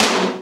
HR16B  TOM 3.wav